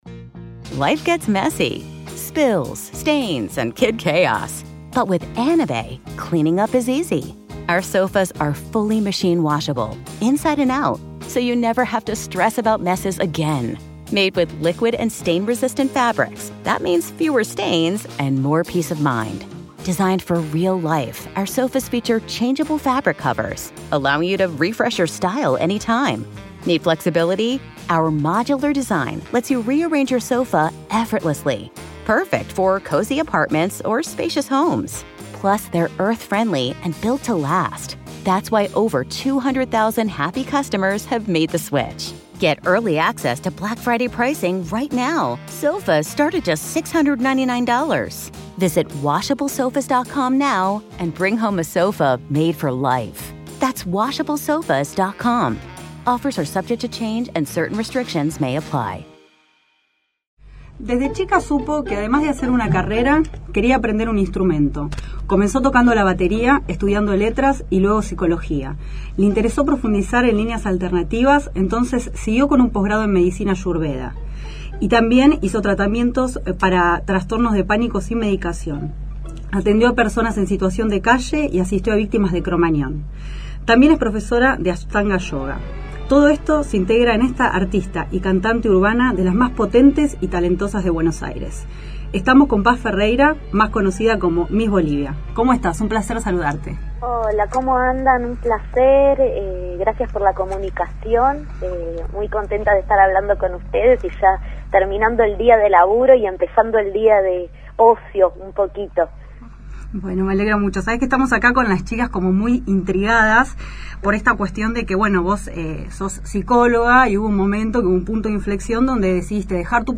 Entrevista Miss Bolivia | Cuando la música cura